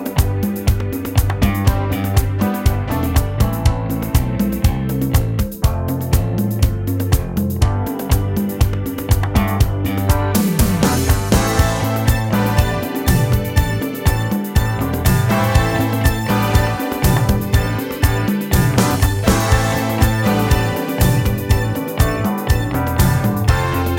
No Lead or Clean Guitars Pop (1980s) 3:52 Buy £1.50